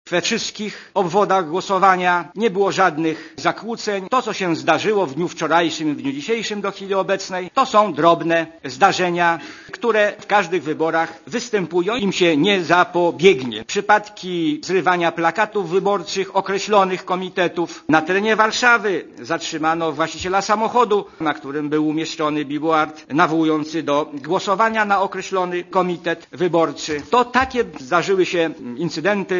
O przebiegu wyborów mówi Jan Kacprzak, przewodniczący PKW (114kB)